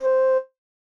Flute.wav